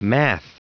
Prononciation du mot math en anglais (fichier audio)
Prononciation du mot : math